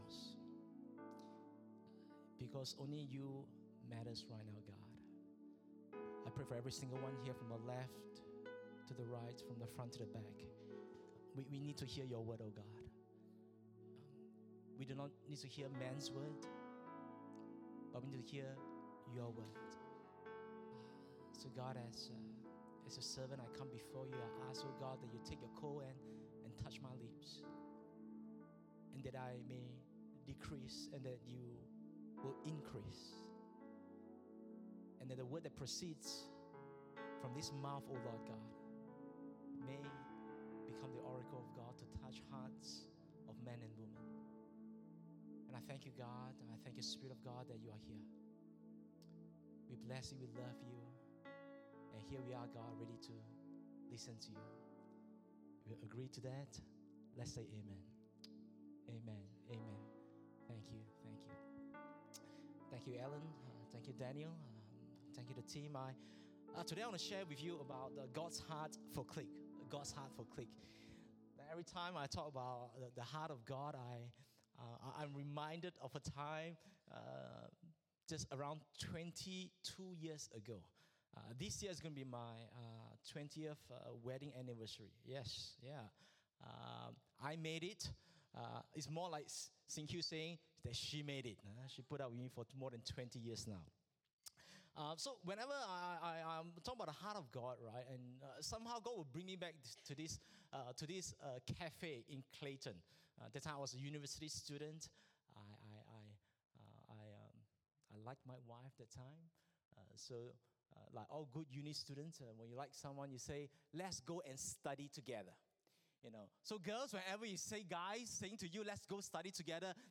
English Worship Service - 12th February 2023